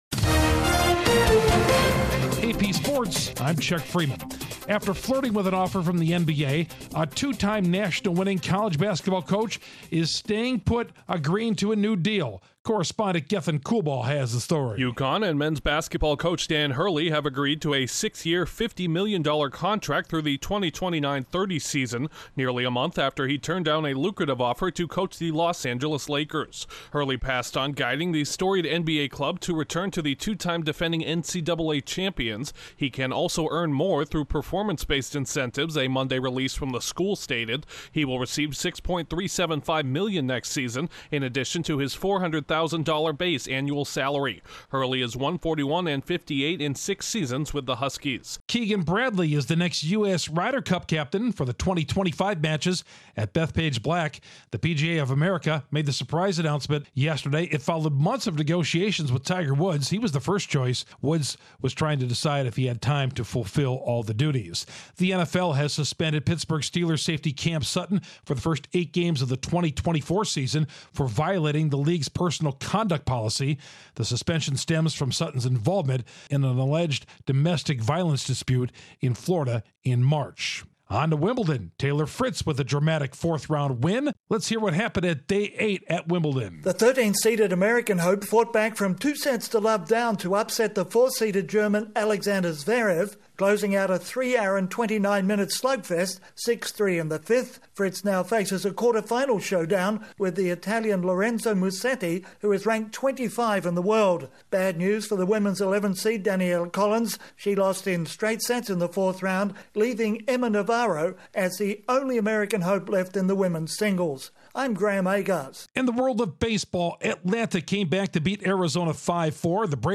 UConn agrees to a contract with coach Dan Hurley, Keegan Bradley named Ryder Cup captain, Day 8 at Wimbledon, and the Cardinals are on a roll. Correspondent